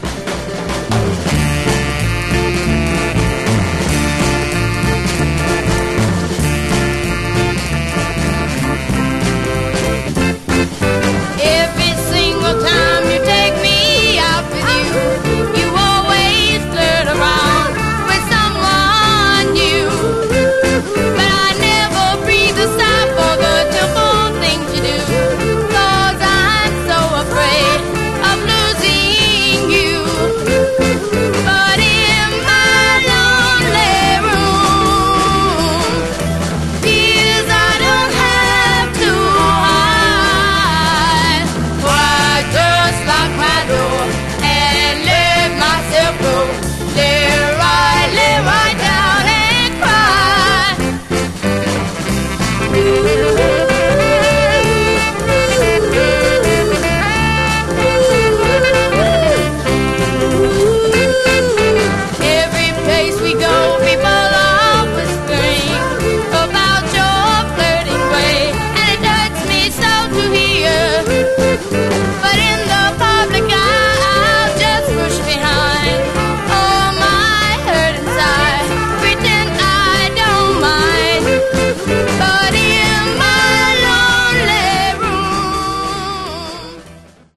The audio sounds pristine after a deep cleaning.